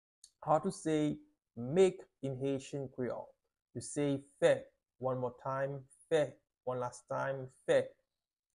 How to say "Make" in Haitian Creole - "Fè" pronunciation by a native Haitian Creole Teacher
“Fè” Pronunciation in Haitian Creole by a native Haitian can be heard in the audio here or in the video below:
How-to-say-Make-in-Haitian-Creole-Fe-pronunciation-by-a-native-Haitian-Teacher.mp3